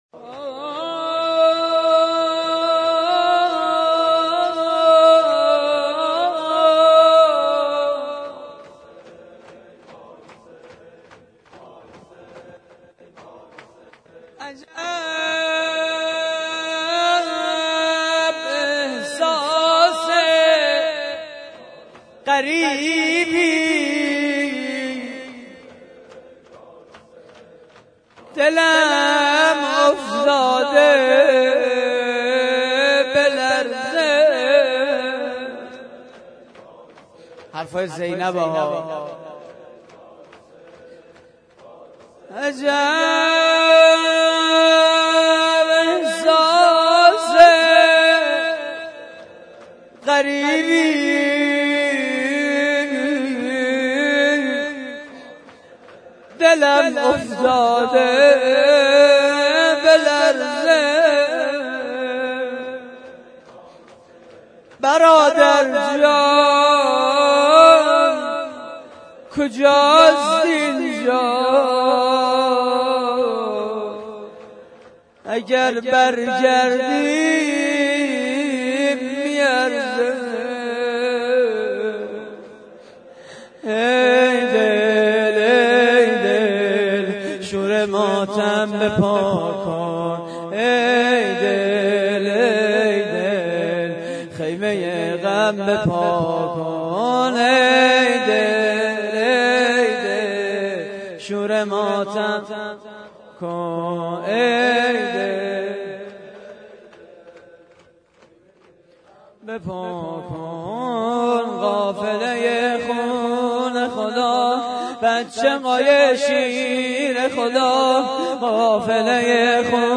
مراسم عزاداری شب دوم ماه محرم / هیئت کریم آل طاها (ع) - شهرری؛ 27 آذر 88
نغمه خوانی و شور: ای دل، خیمه‌ی غم به پا کن؛ پخش آنلاین |